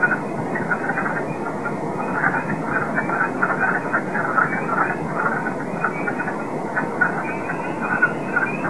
Wood Frog
Voice- a series of hoarse, clacking duck-like quacks.
large chorus (188 Kb)
WoodFrogchorus.wav